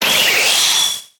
Cri de Galvagon dans Pokémon HOME.